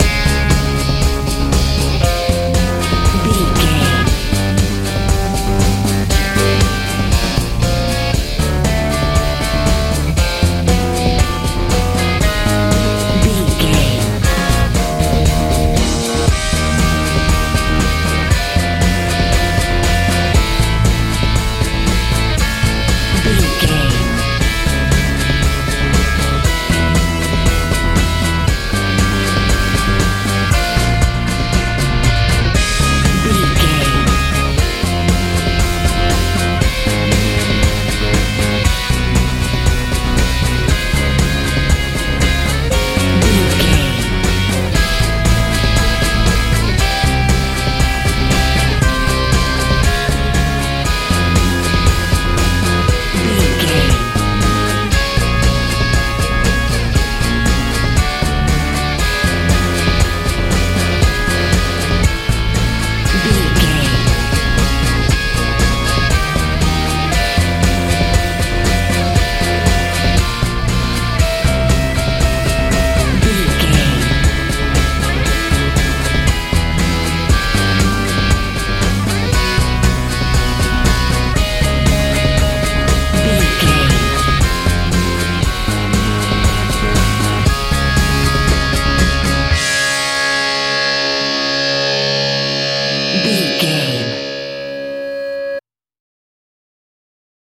industrial rock feel
Ionian/Major
C♯
groovy
funky
electric guitar
bass guitar
drums
90s
2000s